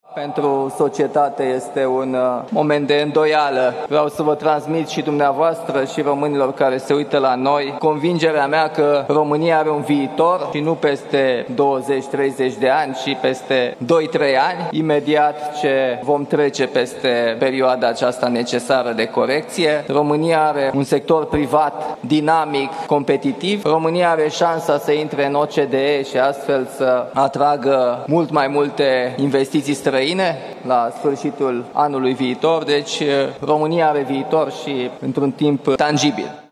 Măsurile necesare asumate de Guvern vor aduce și alte beneficii, precum aderarea mai rapidă la Organizația pentru Cooperare și Dezvoltare, a declarat președintele Dan la congresul extraordinar al PNL: